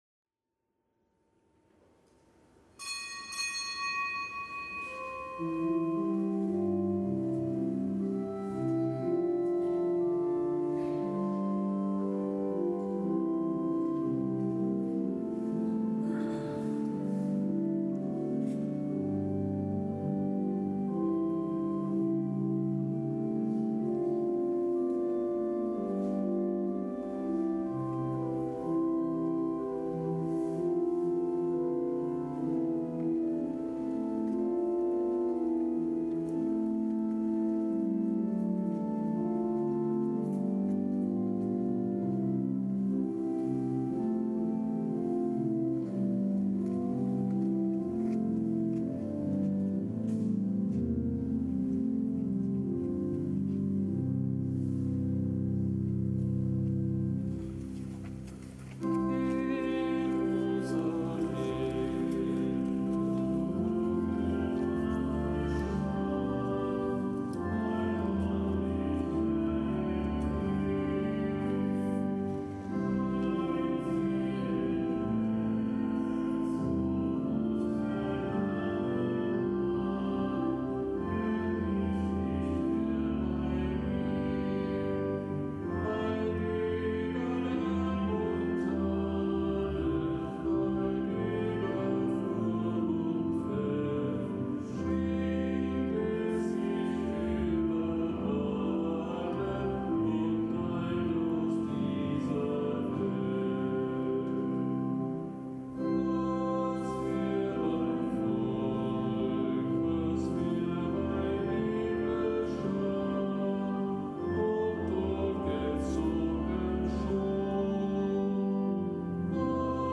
Kapitelsmesse aus dem Kölner Dom am Donnerstag der dreißigsten Woche im Jahreskreis. Zelebrant: Weihbischof Ansgar Puff.